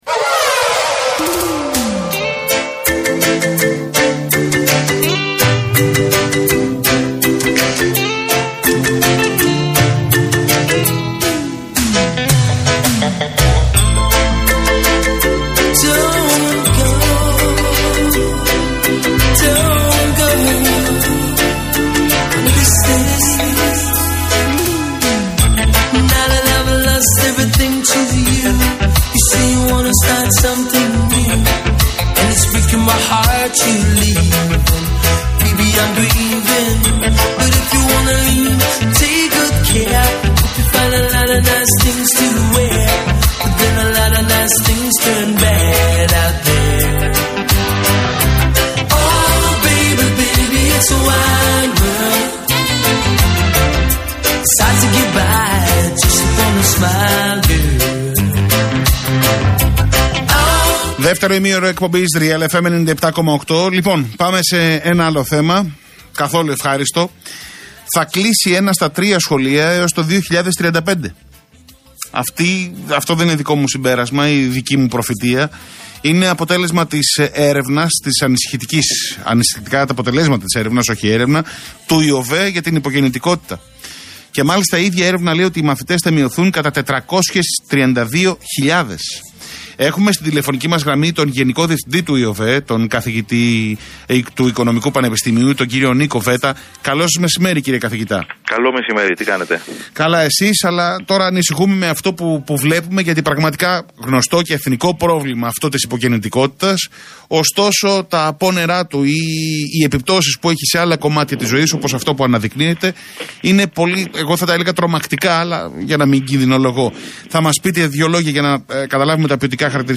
Συνέντευξη στον Real FM